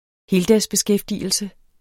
Udtale [ ˈheːldas- ]